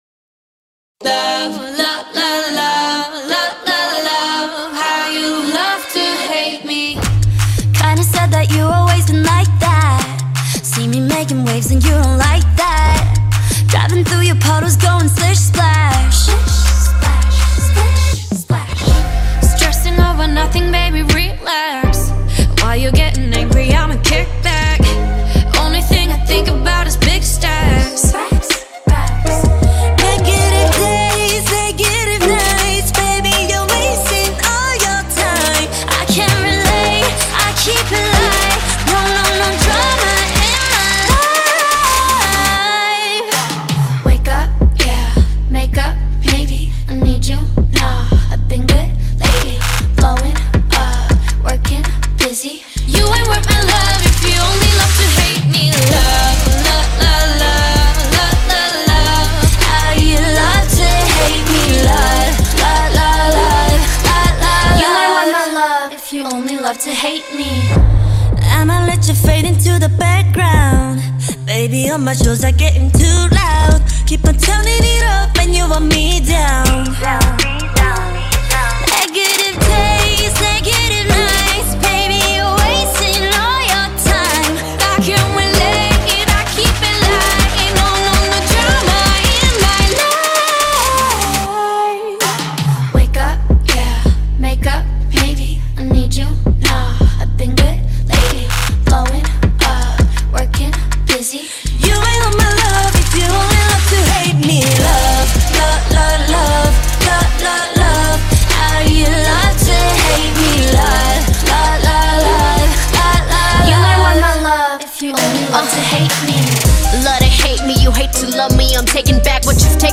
South Korean girl group